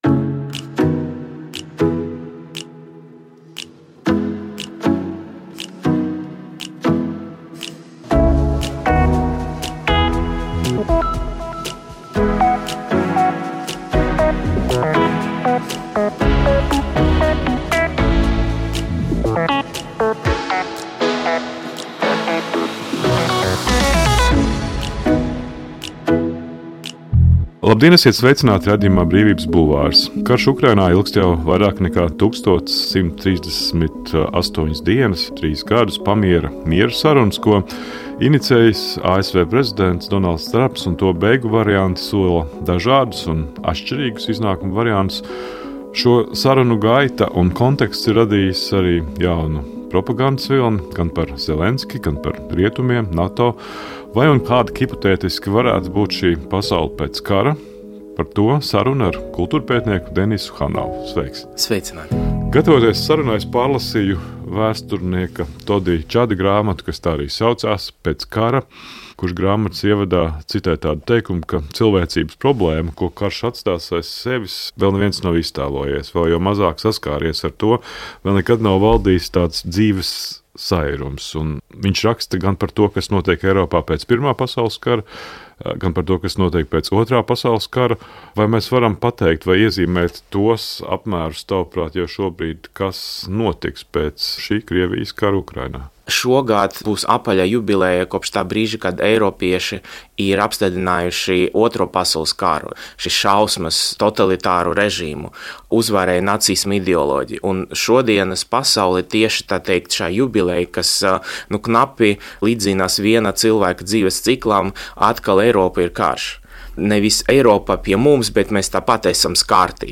Raidījumā Brīvības bulvāris saruna